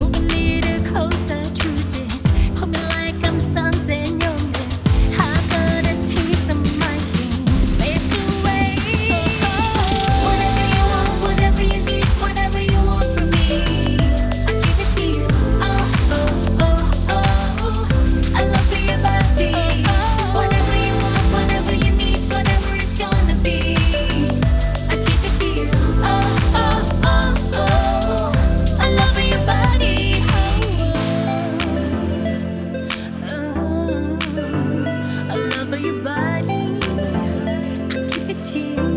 Kategorie POP